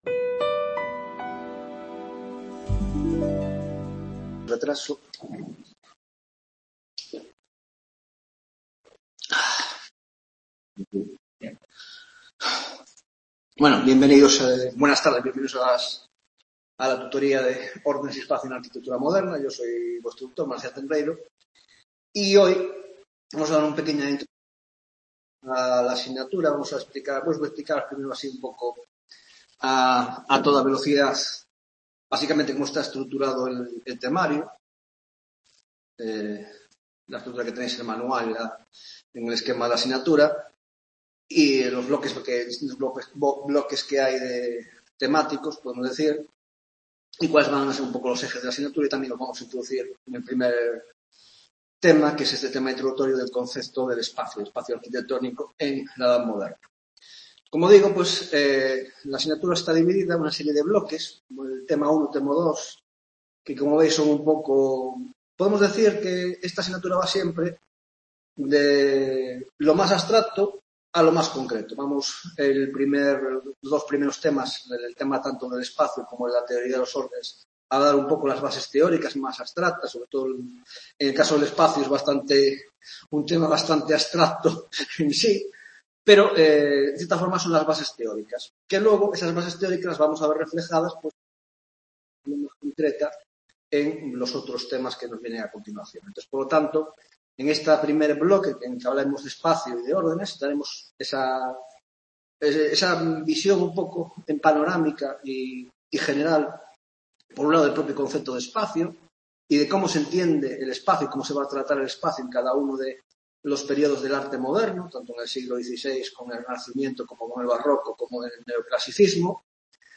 1ª Tutoría de Órdenes y Espacio en la Arquitectura del XV - XVIII - Introducción: La Concepción del Espacio, 1ª Parte